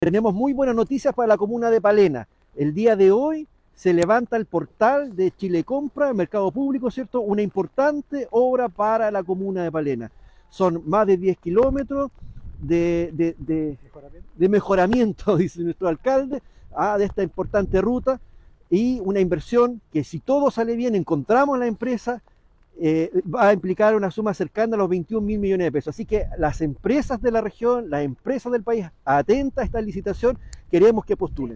Así lo confirmó el Seremi de Obras Públicas en Los Lagos, Juan Fernando Alvarado.